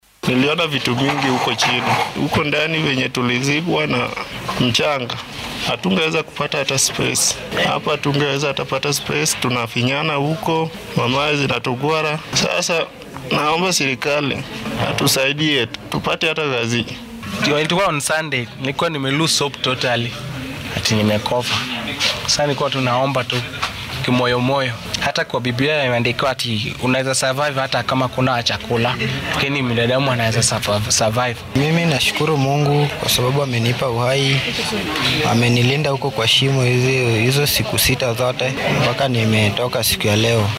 Xilli ay warbaahinta la hadleen iyagoo isbitaalka ku sugan ayay dareenkooda sidan u muujiyeen